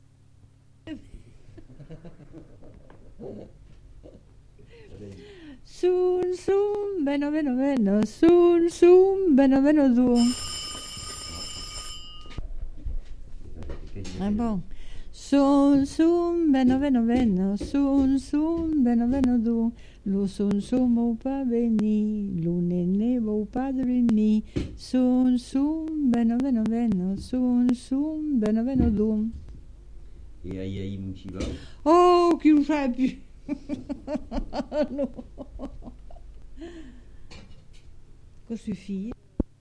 Aire culturelle : Haut-Agenais
Genre : chant
Type de voix : voix de femme
Production du son : chanté
Classification : som-soms, nénies